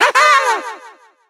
evil_gene_vo_13.ogg